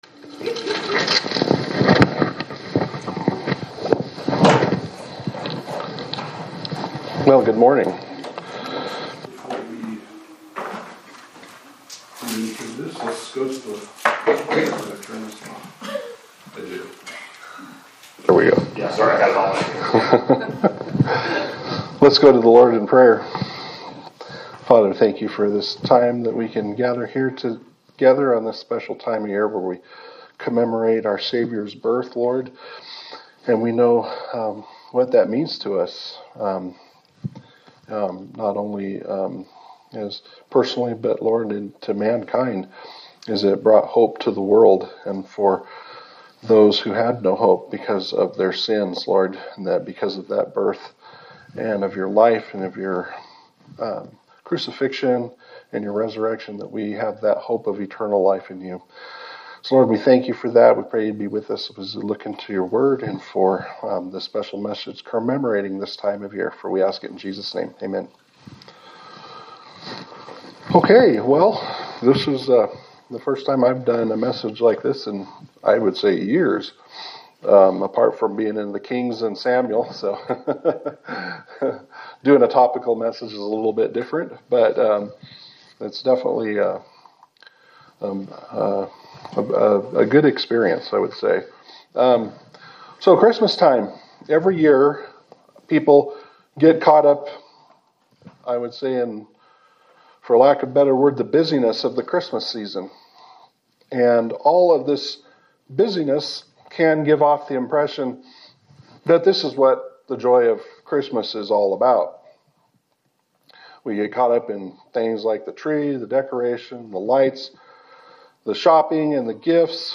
Sermon for December 21, 2025
Service Type: Sunday Service